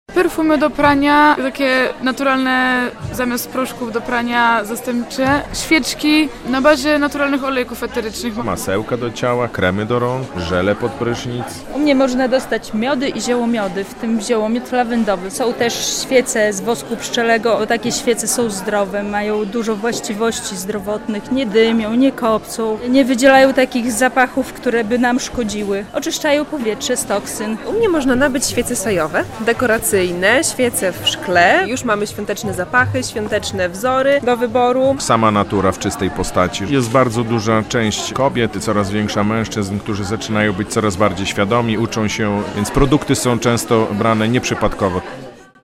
W ten weekend na Stadionie Miejskim w Białymstoku odbywają się Targi Rzeczy Naturalnych EkoLove.
relacja